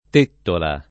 tettola [ t % ttola ]